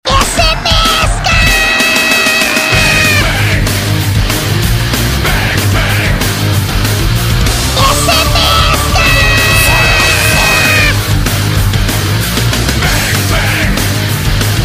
Смска — аааа…